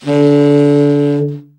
BRA_TEN SFT    5.wav